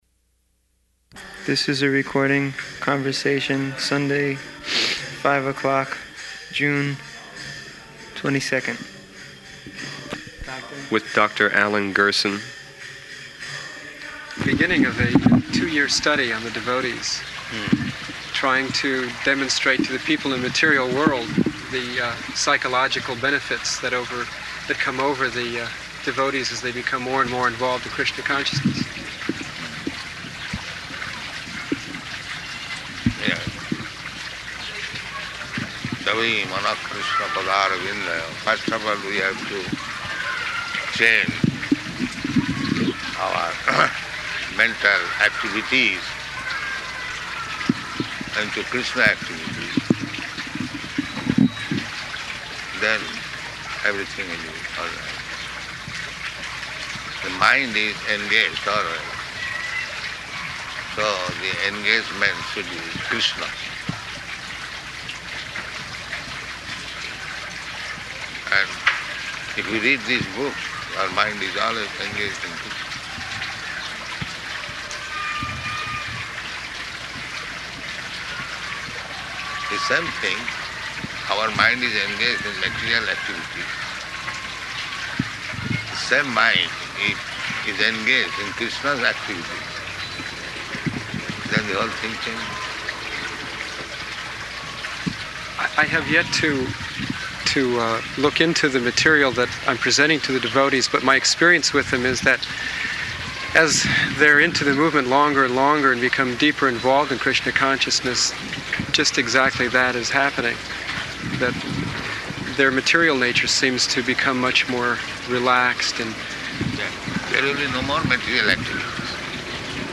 Garden Conversation
Type: Conversation
Location: Los Angeles